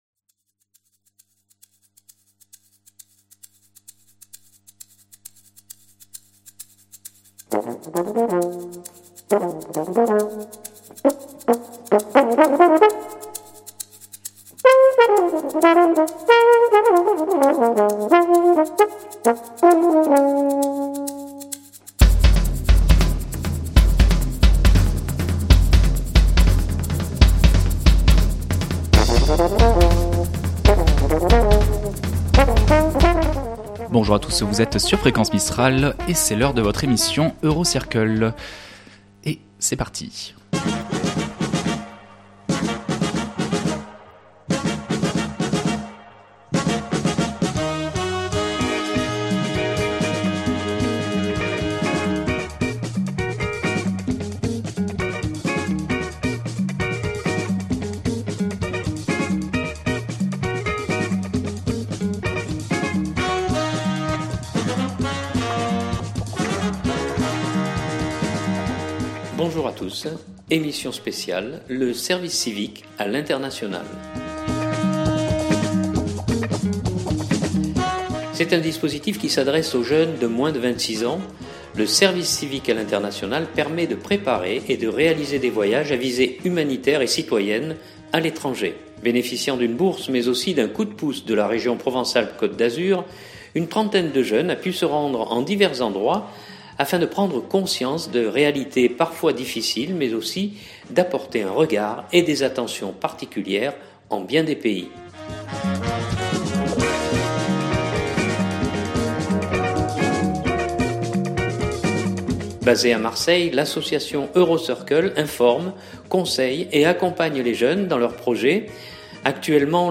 Pendant cette période de fêtes Fréquence Mistral vous propose des rediffusions d'émissions qui auront marqué l'année qui s'achève.
Nous avons diffusé une émission spéciale le 11 mars consacrée à ce sujet et où des Provençaux de moins de 26 ans nous ont raconté leur expérience au Togo, au Japon ou en Bolivie etc.